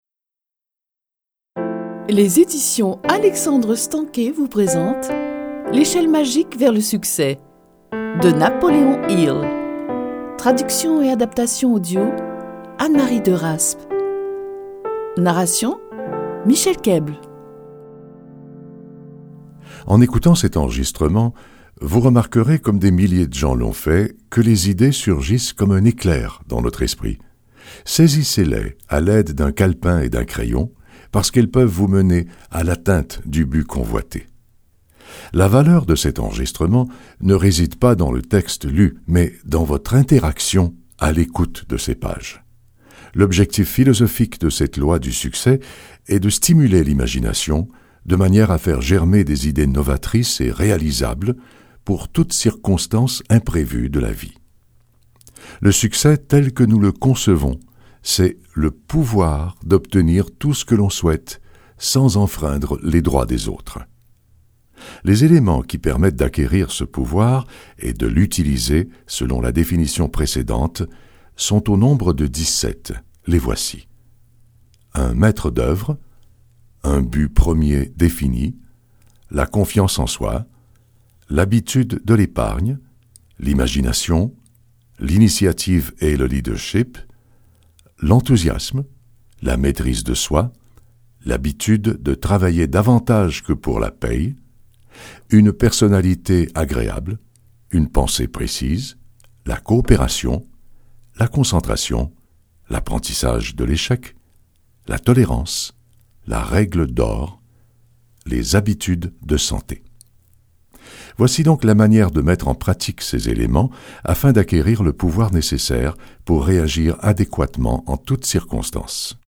0% Extrait gratuit L'échelle magique vers le succès La magie de voir grand de Napoleon Hill Éditeur : Coffragants Paru le : 2018 La magie à laquelle Napoléon Hill fait référence dans ce livre audio n’a rien à voir avec l’astrologie, la fantasmagorie ou les sciences occultes.